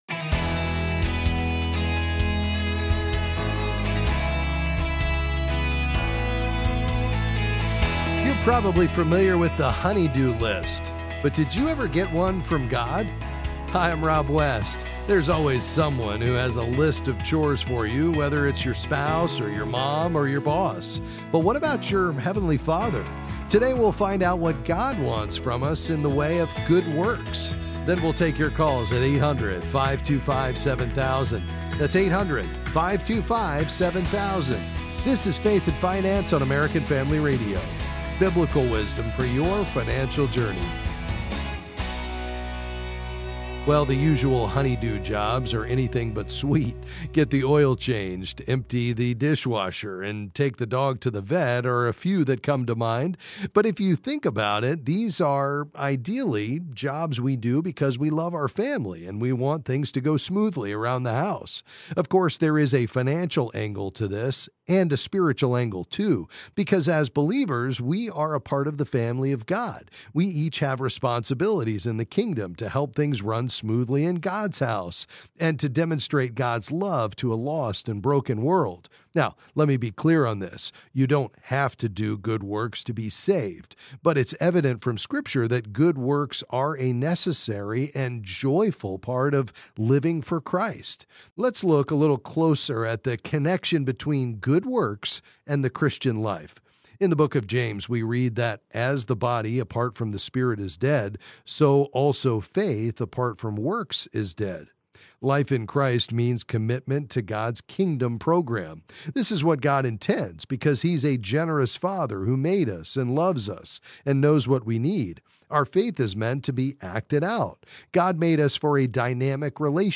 Then he answers your calls and various financial questions.